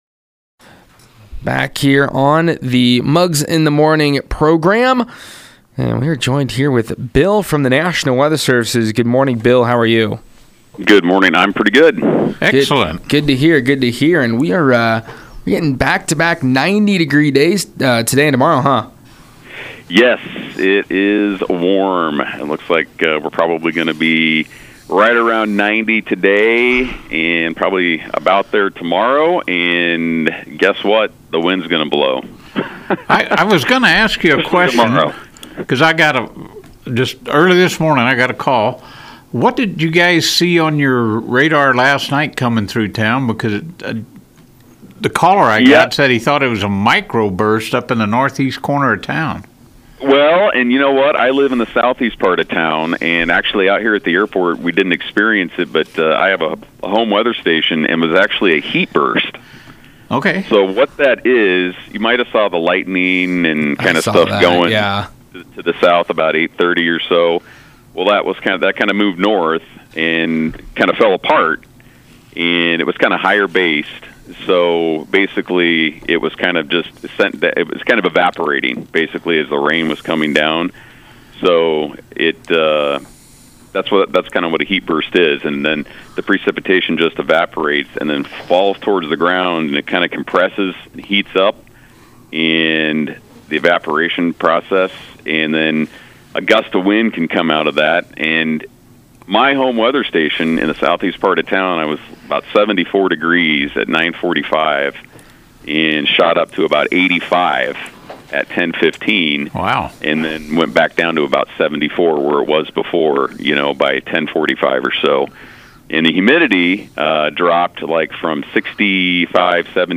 Interview: The National Weather Services Discusses This Week’s Weather
nws-interview.mp3